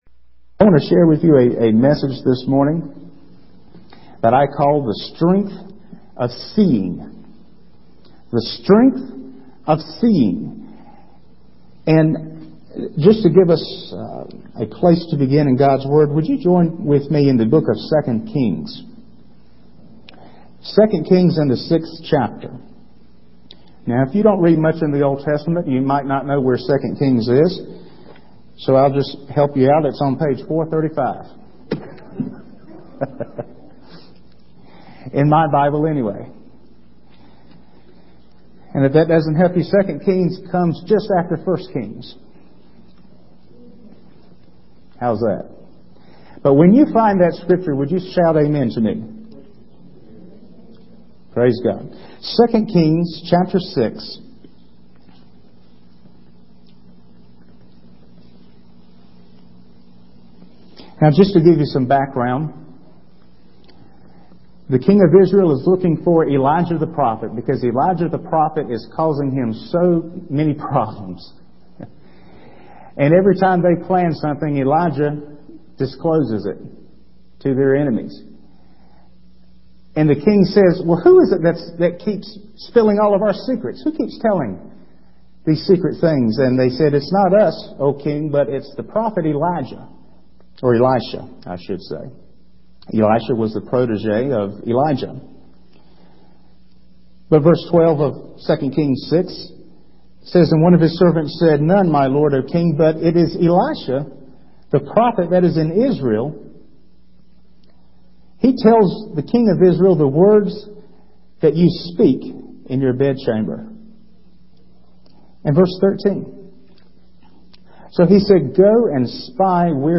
In this sermon, the speaker addresses the desperate times we are living in, with conflicts, terrorism, and immorality plaguing the world.